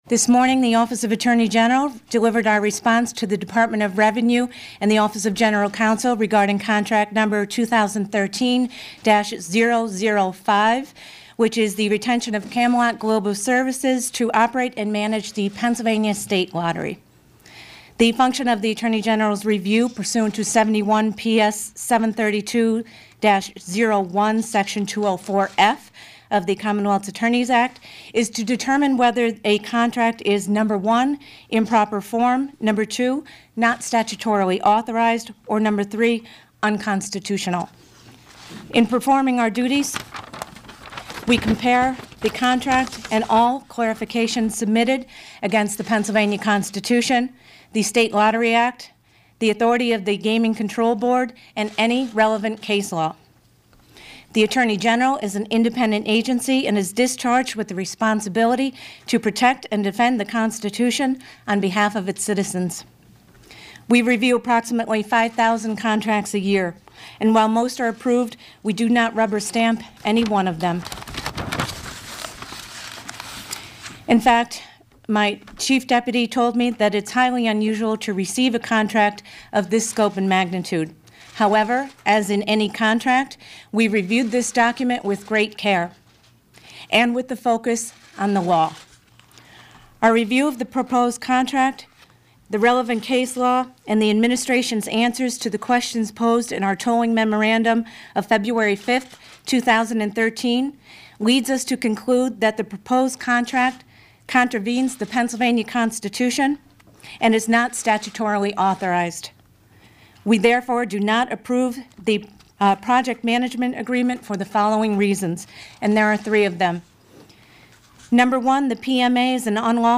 Kane addressed the media for about five minutes this afternoon.